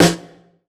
SNARE 074.wav